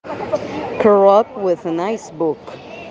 Atenttion to pronunciation: